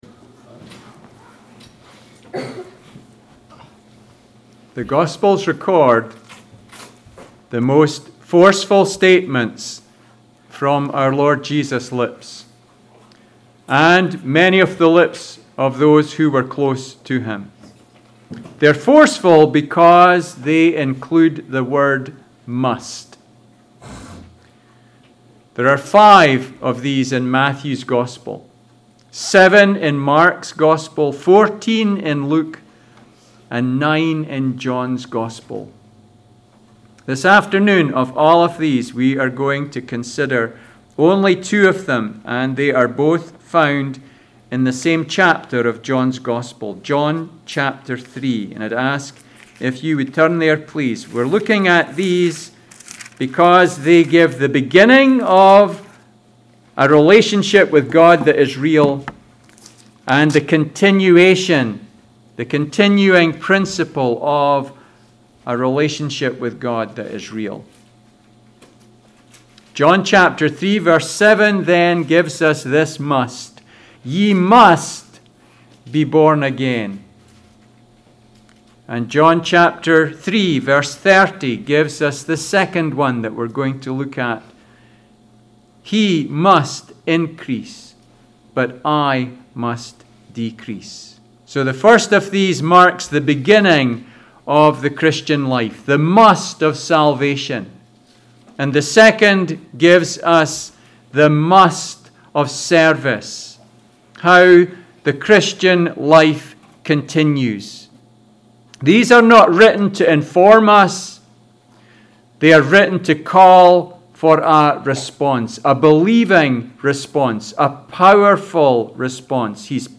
Passage: John 3:30 Service Type: Sunday School